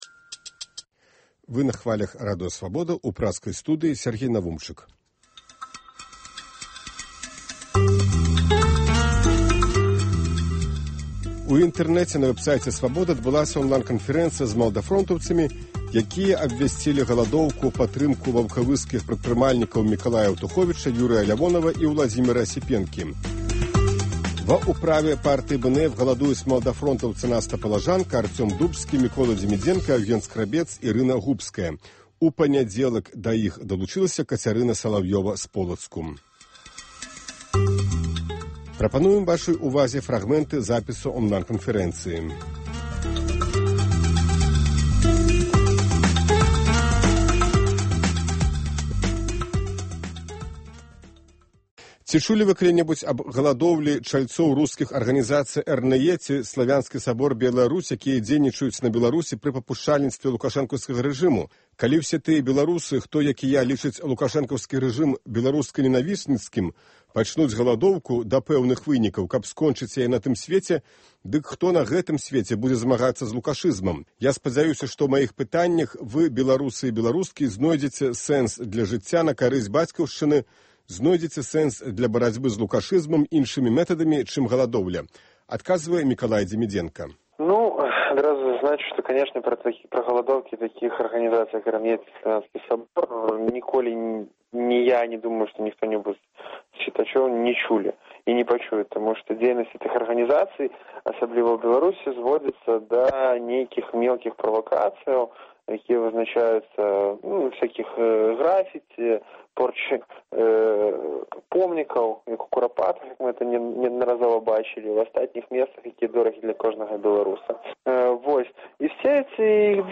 Онлайн- канфэрэнцыя
Онлайн-канфэрэнцыя з маладафронтаўцамі, якія абвясьцілі галадоўку ў падтрымку арыштаваных ваўкавыскіх прадпрымальнікаў.